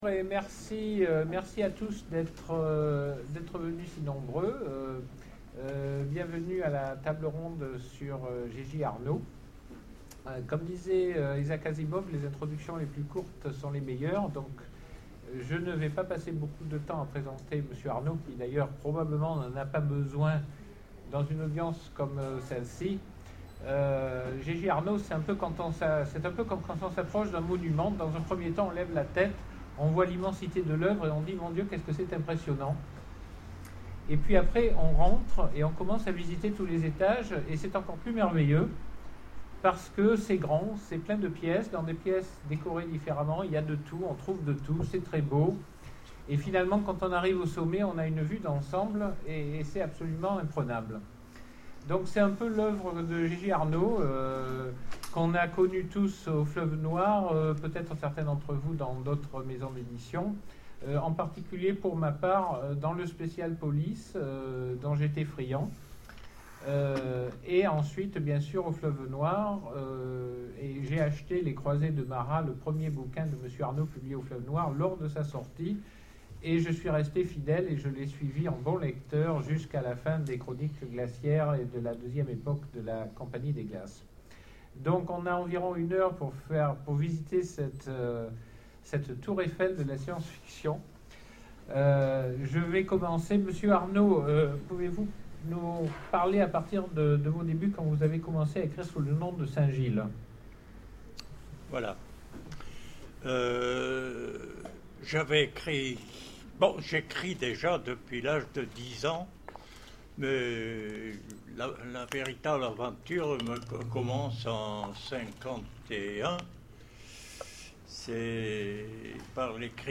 9ème Rencontres de l'Imaginaire de Sèvres : Conférence G.-J. Arnaud, invité d’honneur
9ème Rencontres de l'Imaginaire de Sèvres : Conférence G.-J. Arnaud, invité d’honneur Télécharger le MP3 à lire aussi Jean-Marc Lofficier G-J Arnaud Genres / Mots-clés Rencontre avec un auteur Conférence Partager cet article